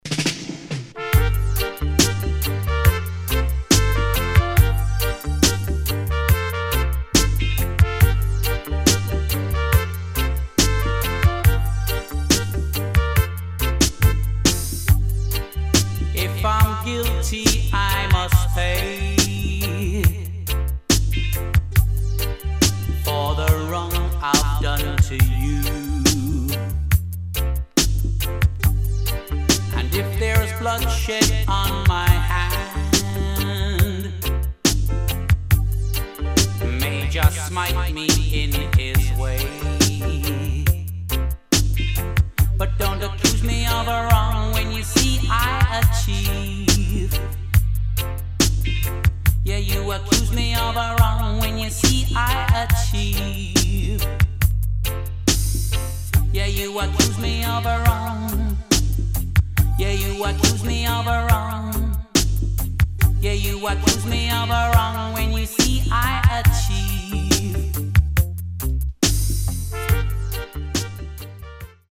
[ REGGAE / DUB ]